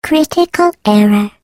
Portal Turret Critical Error Sound Effect Free Download